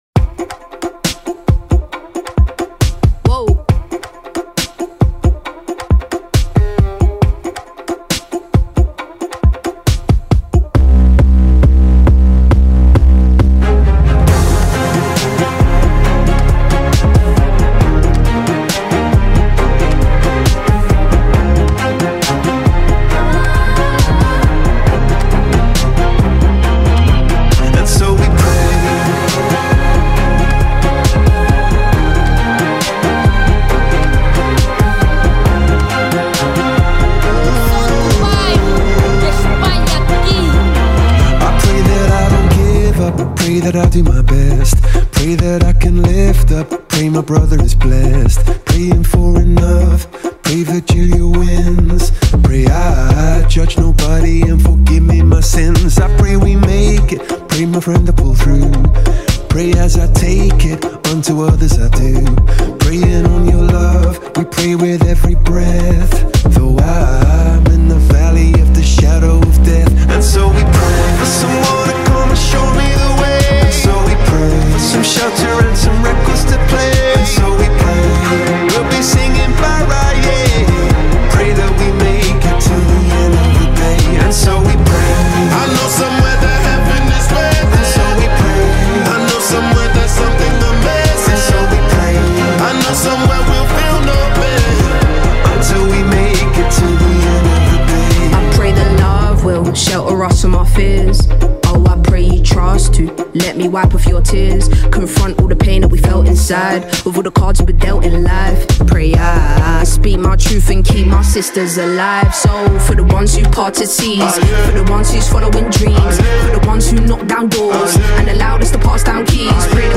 Genero: Afro Beat